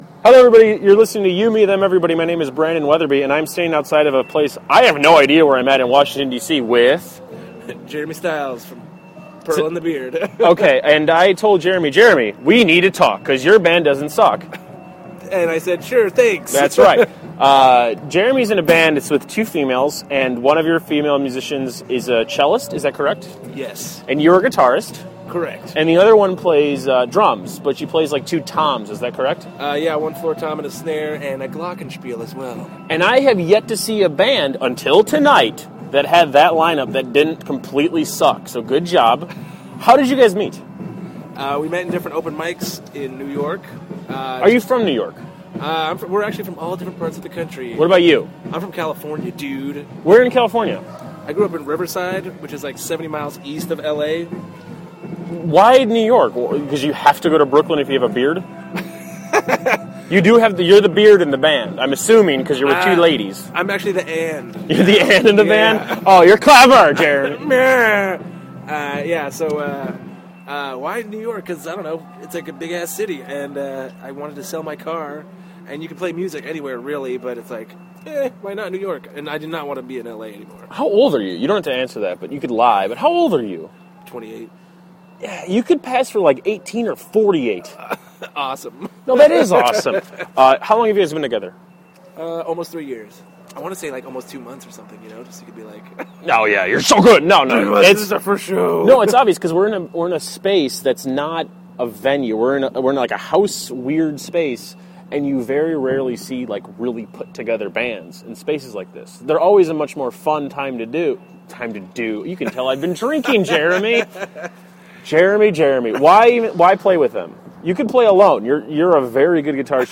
Rather than stay angry per usual, I decided to ask for an interview.